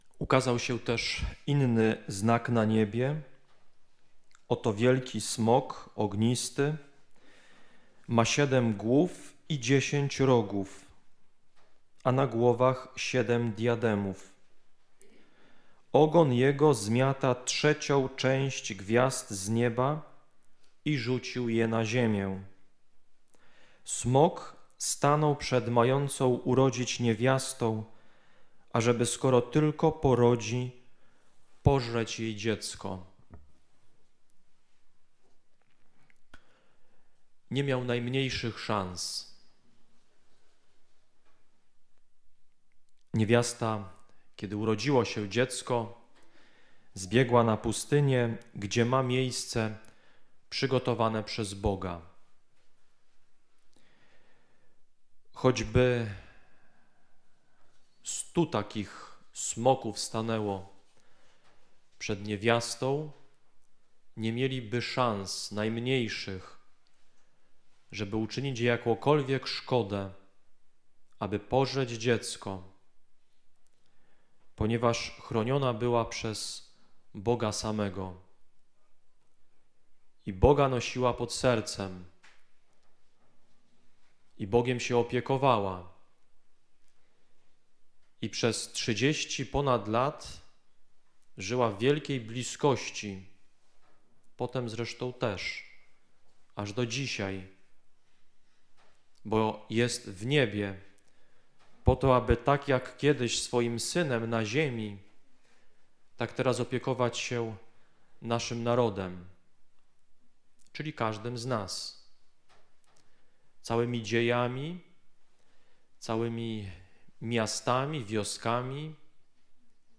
Uroczystość NMP Królowej Polski – homilia: Matka na każdy czas [audio]